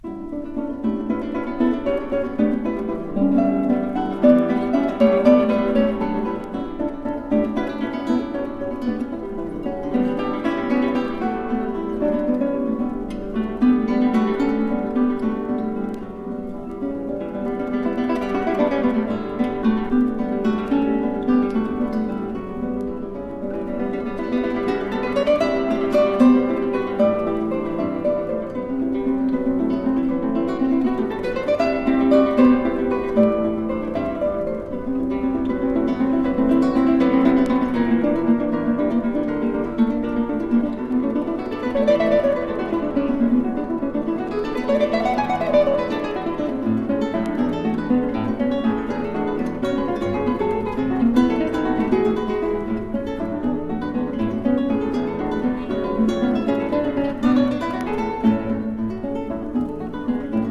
ブラジリアン・クラシカル・ギターデュオ ’85年作。